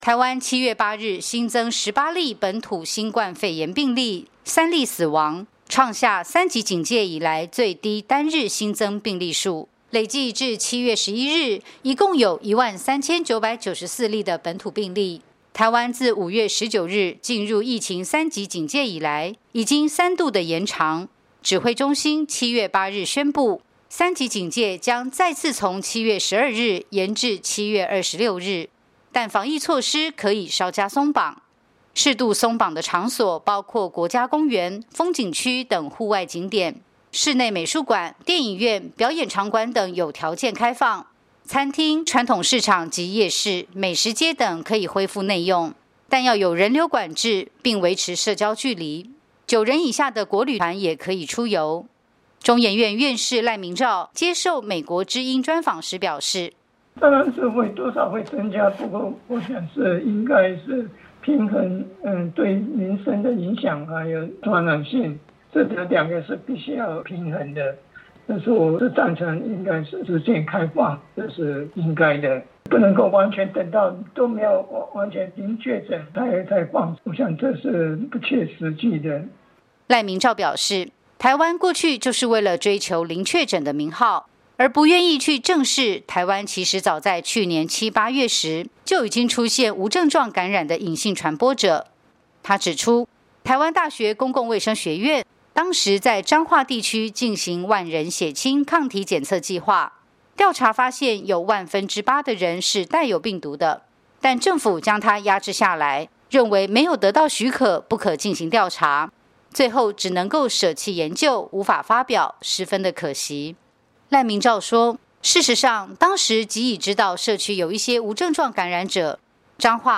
台湾中央流行疫情指挥中心日前宣布，台湾三级警戒将延长至7月26日，但自13日起“微解封”，适度开放餐饮内用和户外风景游乐区等。有“台湾冠状病毒之父”称号的中央研究院院士赖明诏在接受美国之音专访时表示，支持指挥中心“微解封”的决定，防疫跟民生必需取得平衡，如果纠结在零确诊的数字上才开放，反而不切实际。